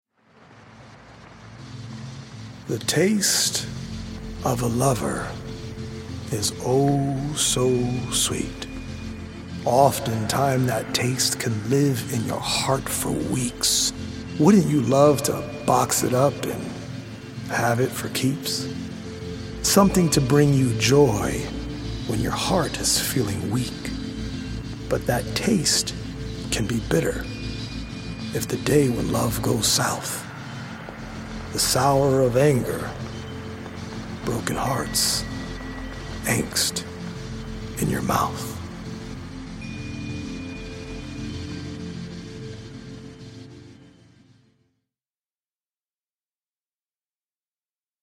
healing audio-visual poetic journey
healing Solfeggio frequency music